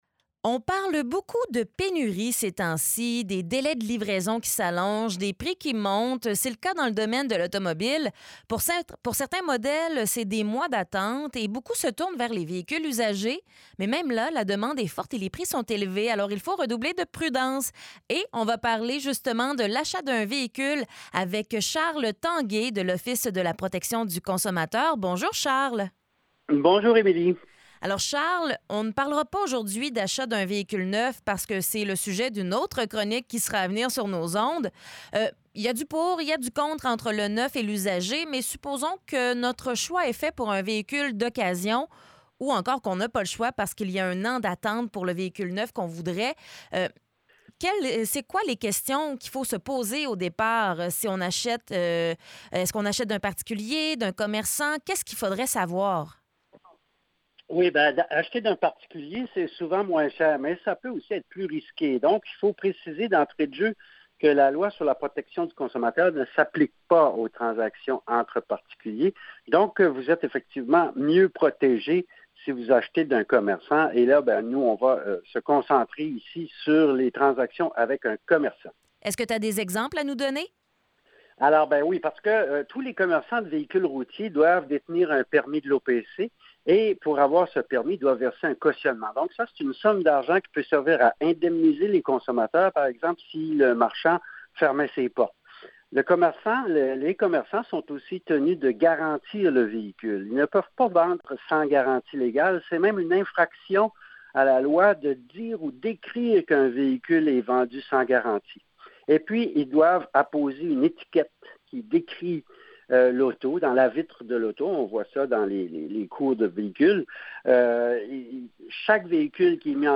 entrevue_auto_usagee.mp3